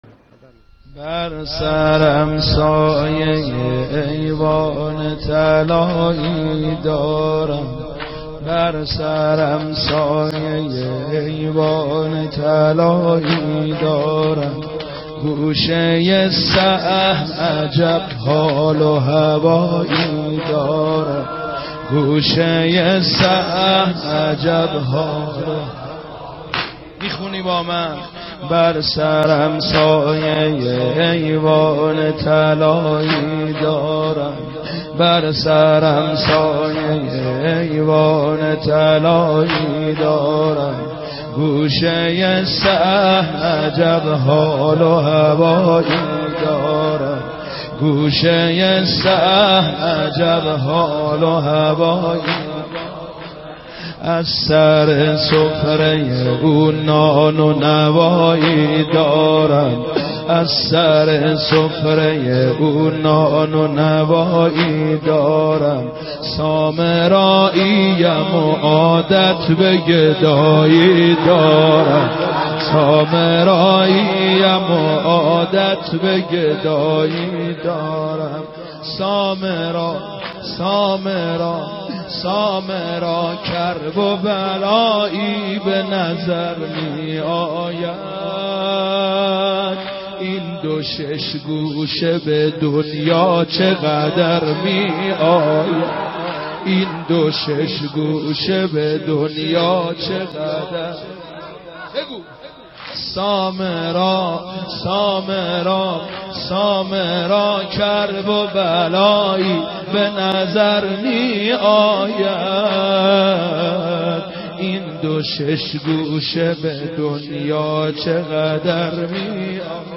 یک نوحه ی زیبا به مناسبت شهادت امام علی النقی علیه السلام در شب شهادت ایشان یعنی 29 اسفند 96 از شبکه ی قرآن پخش شد که بسیار زیبا بود:
این دوبیت، قسمتی از این نوحه ی زیبا بود.
اسم مداحش را نمی دانم ولی مداح معروفی بود و صدایش را زیاد شنیده بودم.